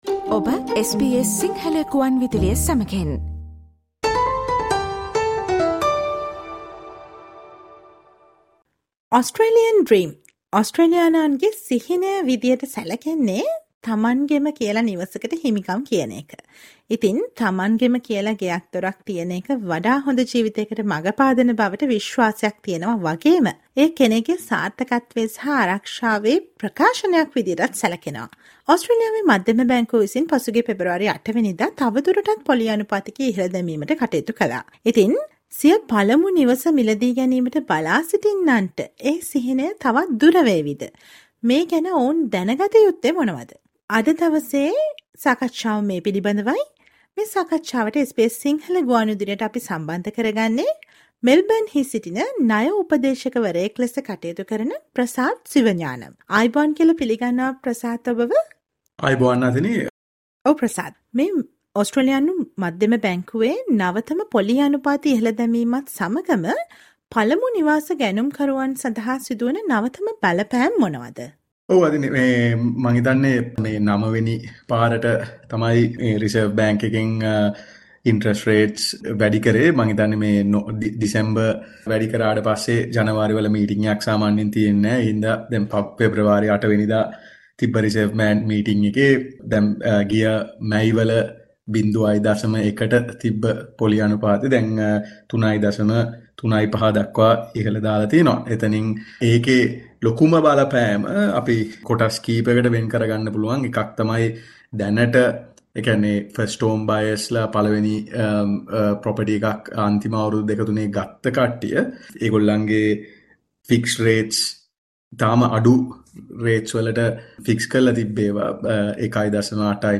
දැන ගන්න සවන් දෙන්න මේ SBS සිංහල ගුවන් විදුලි සාකච්ඡාවට